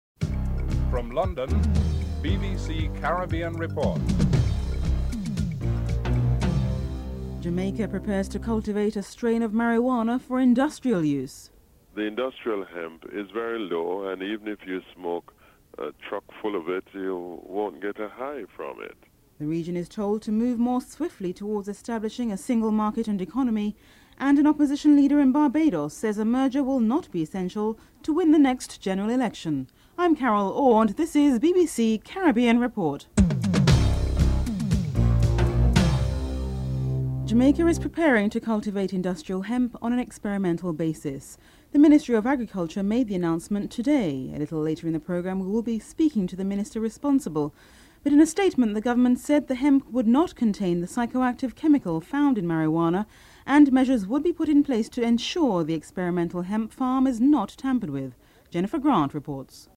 1. Headlines (00:00-00:35)
Prime Minister Basdeo Panday is interviewed (05:06-07:24)